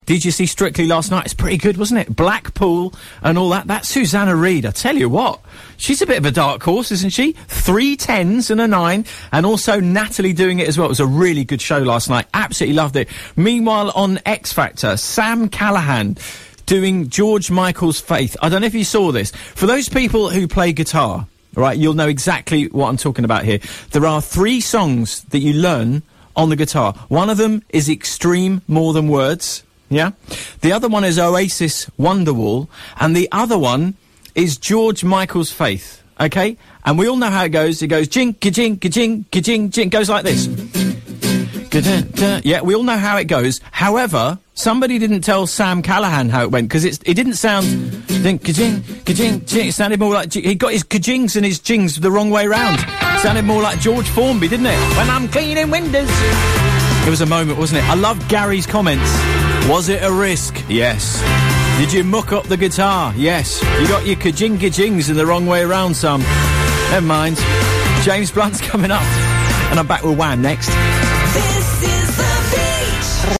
That guitar playing on X Factor
Sam Callahan's guitar playing on X Factor